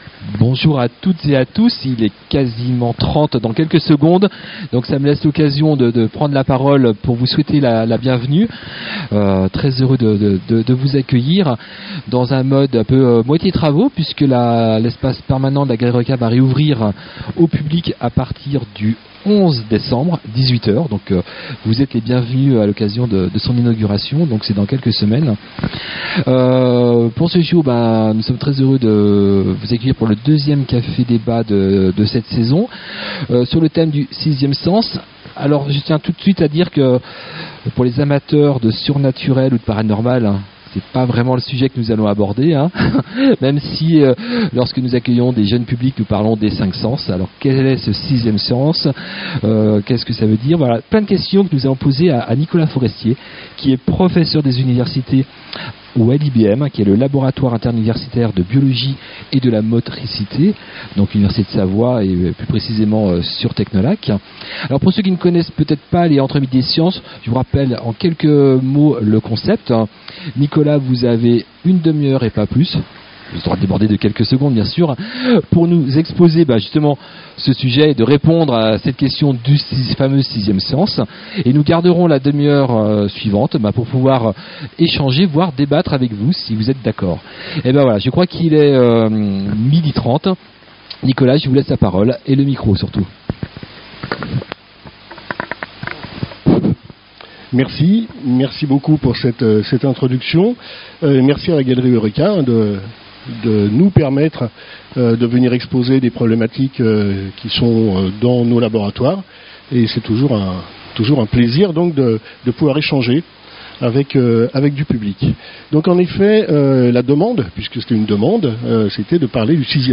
Une fois par mois, à la Galerie Eurêka, venez rencontrer des spécialistes, poser vos questions et débattre avec eux lors des rendez-vous « Entre midi & science ».